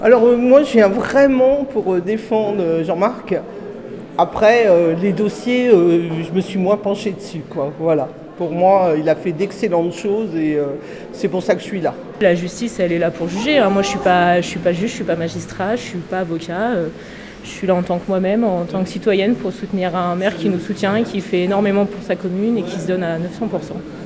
ITG Micro trot 3 - Soutien Peillex Saint Gervais (24’’)